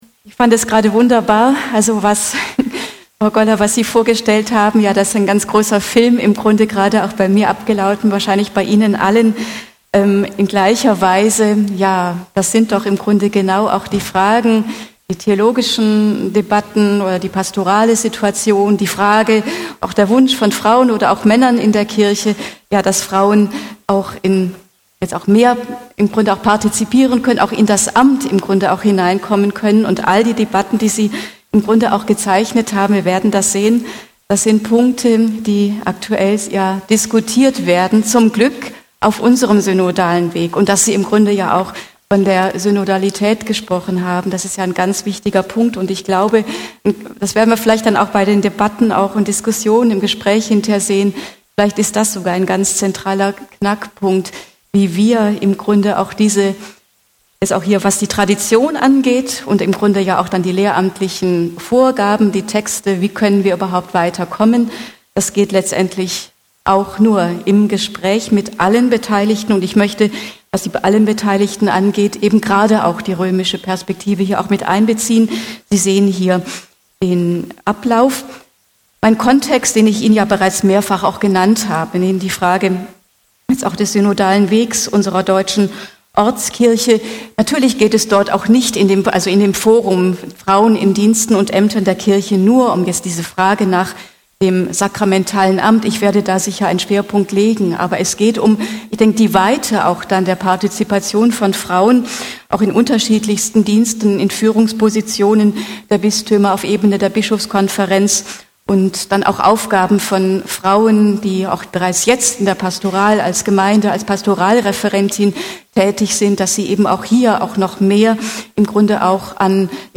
Audiomitschnitt zu Frauen und Ämter in der Kirche
Da die Veranstaltung mit mehr als 2 Stunden recht lang war, haben wir den Mitschnitt in fünf verschiedene Dateien aufgeteilt.